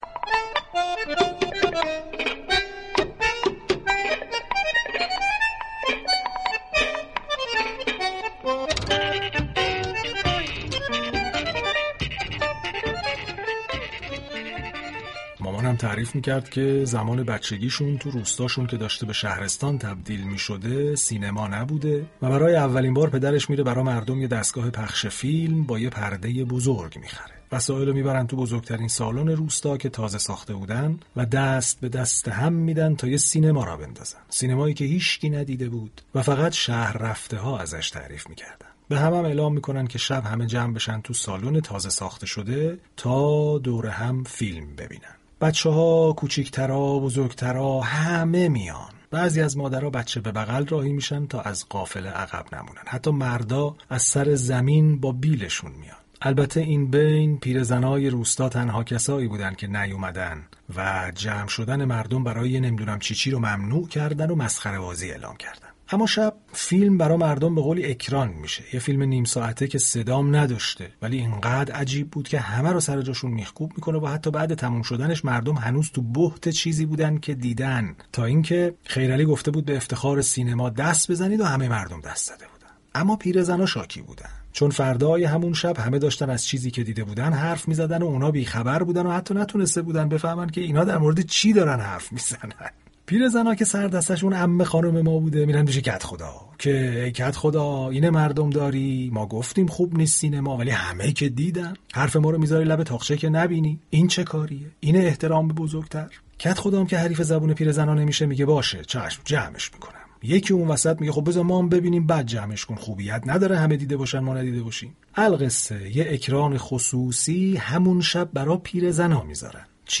به گزارش روابط عمومی رادیو صبا، «به توان هفت» عنوان یك برنامه فرهنگی است، كه در روزهای پاییزی به ورق زدن دنیای ادبیات و سینما در كنار زندگی می پردازد.